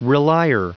Prononciation du mot relier en anglais (fichier audio)
Prononciation du mot : relier